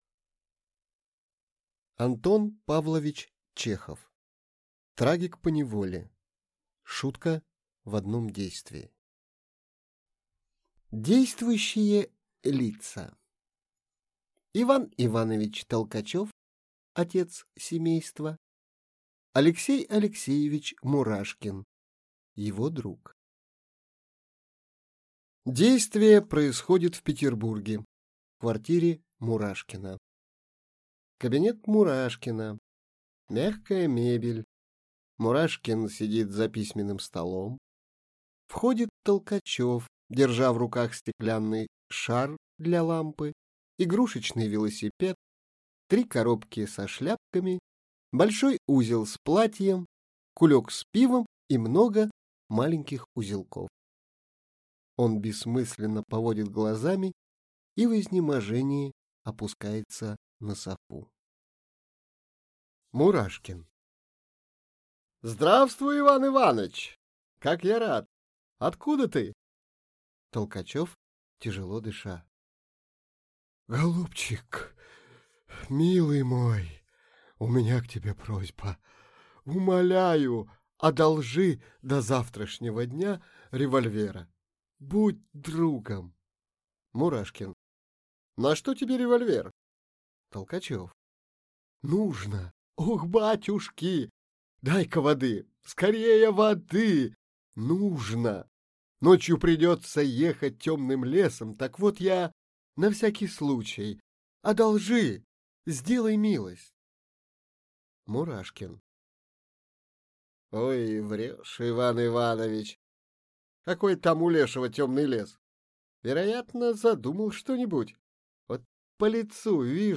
Аудиокнига Трагик поневоле (из дачной жизни) (шутка в одном действии) | Библиотека аудиокниг